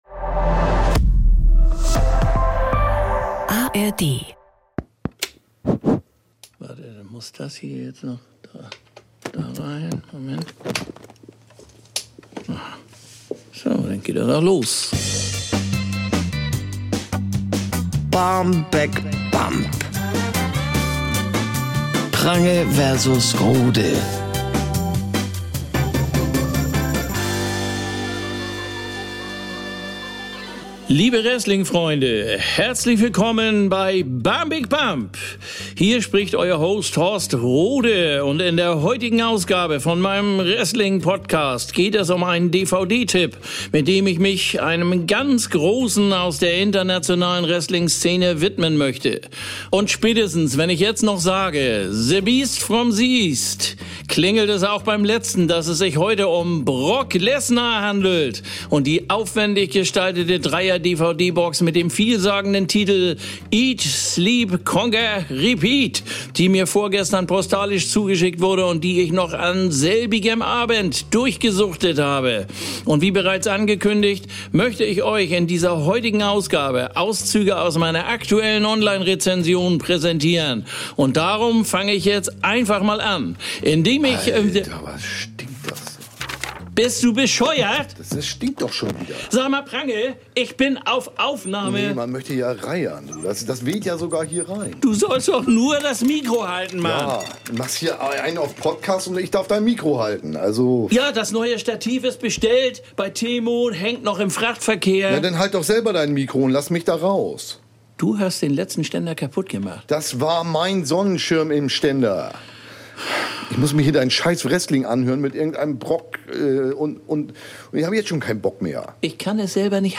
Ralf Prange: Bjarne Mädel Horst Rohde: Olli Dittrich Sprecherin: Doris Kunstmann